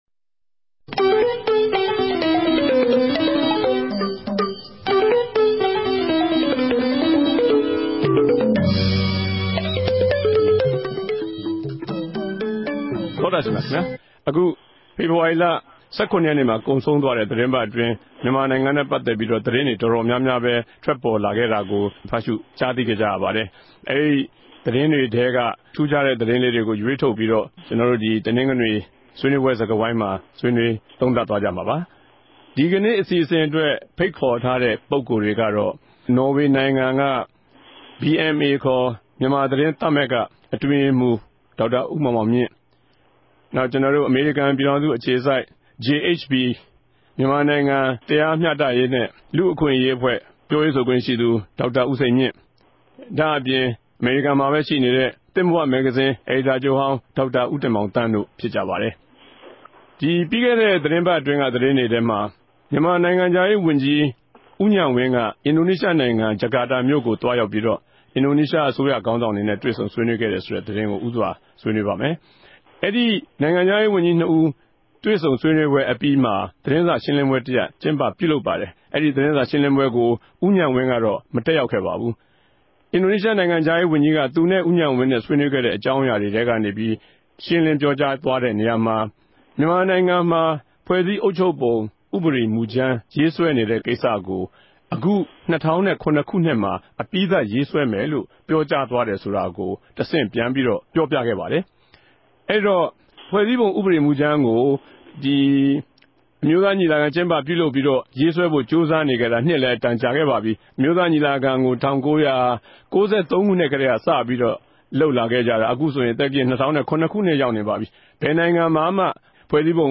႟ုံးခဵြပ် စတူဒီယိုကနေ တယ်လီဖုန်းနဲႛ ဆက်သြယ်္ဘပီး၊